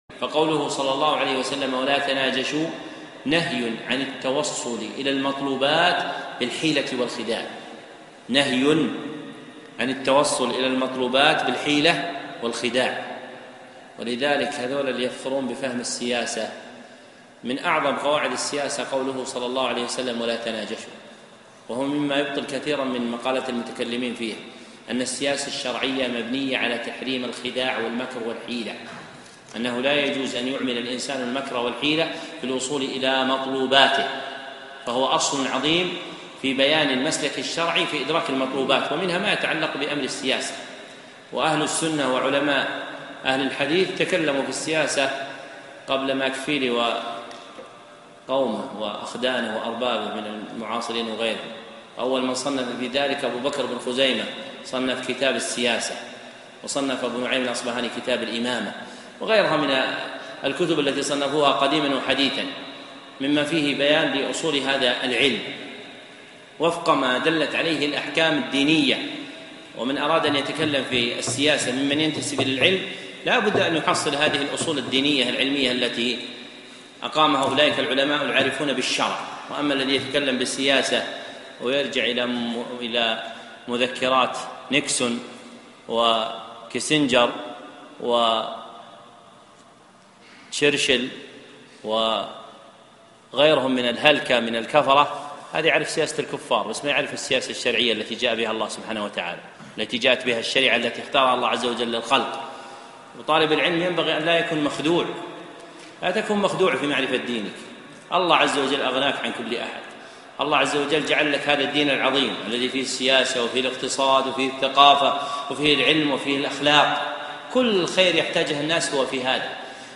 موعظة عظيمة في السياسة